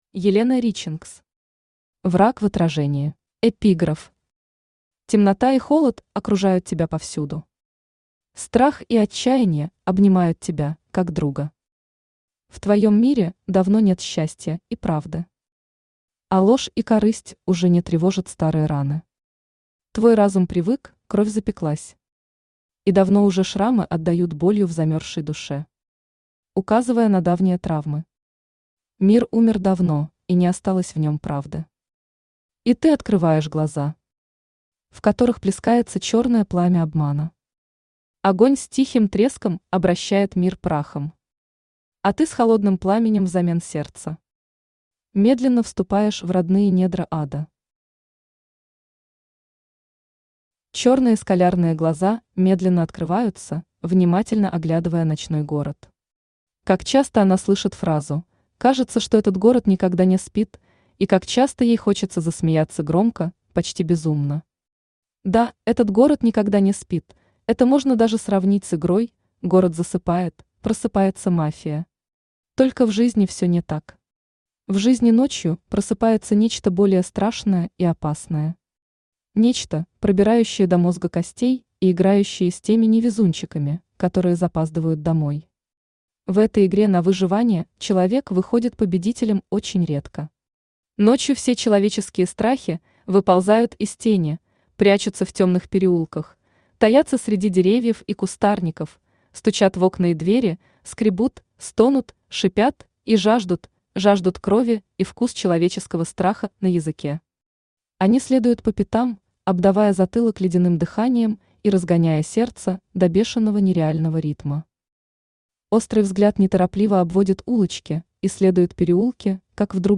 Аудиокнига Враг в отражении | Библиотека аудиокниг
Aудиокнига Враг в отражении Автор Елена Ричингс Читает аудиокнигу Авточтец ЛитРес.